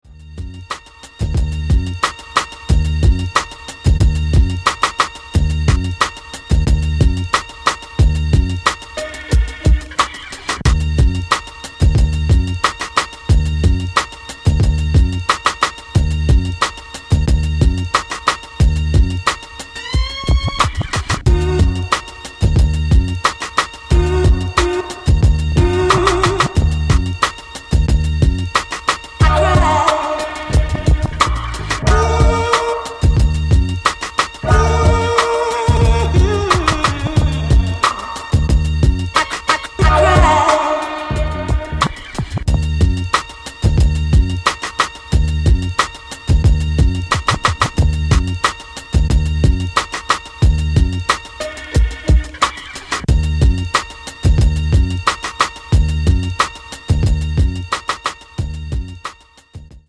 Heavy instrumental jams